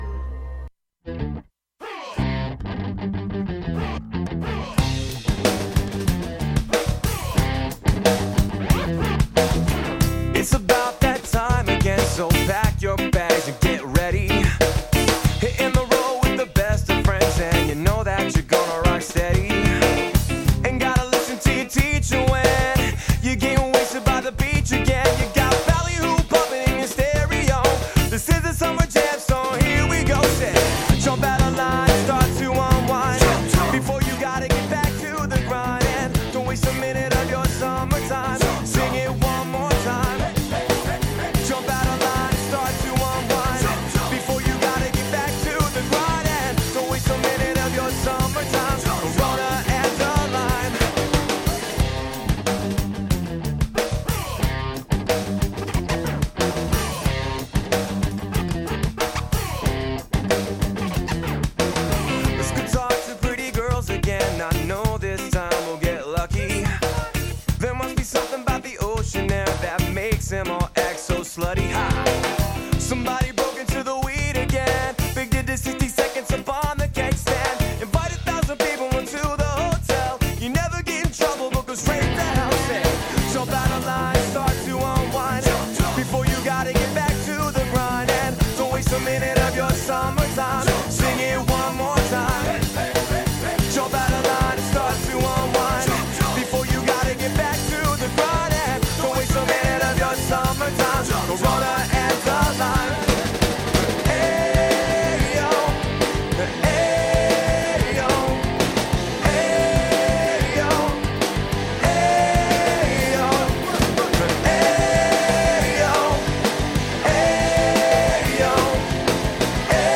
The Stroll bros decide to joke around with fake guest "Sally Fingers" a Brooklyn Italian restaurateur with a craving for old school video games